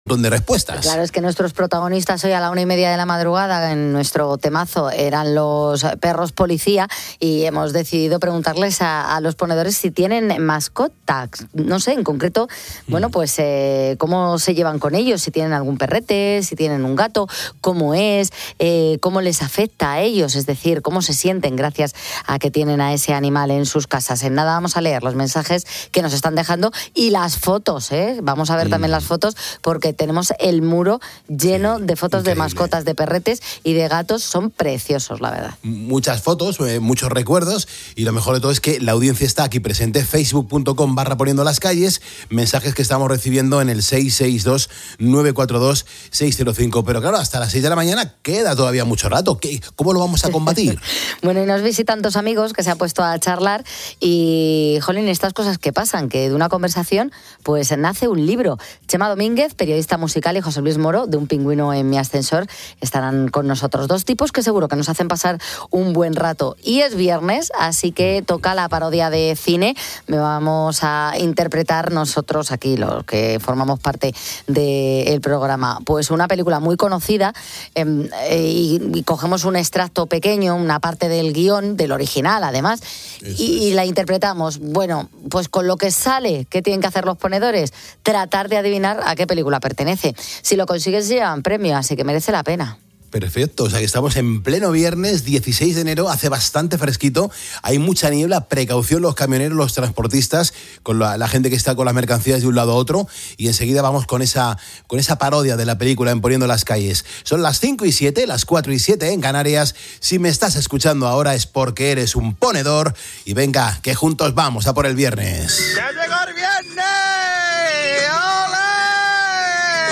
A través de mensajes y audios, la audiencia ha compartido cómo perros , gatos y otros animales de compañía se han vuelto piezas fundamentales de sus vidas, llenando sus hogares de alegría y compañía .